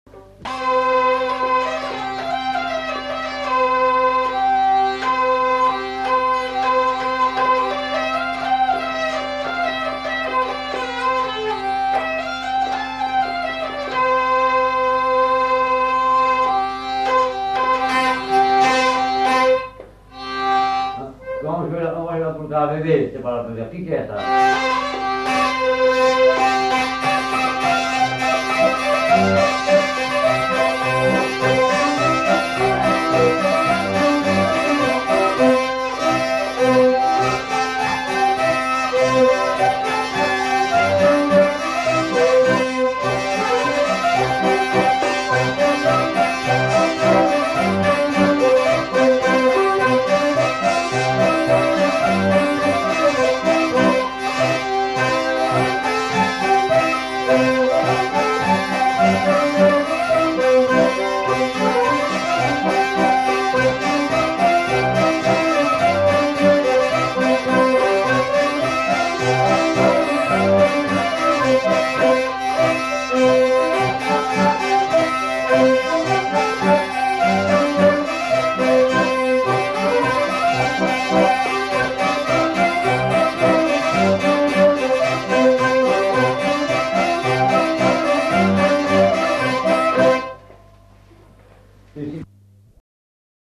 Aire culturelle : Gabardan
Lieu : Herré
Genre : morceau instrumental
Instrument de musique : vielle à roue ; accordéon diatonique
Danse : polka des bébés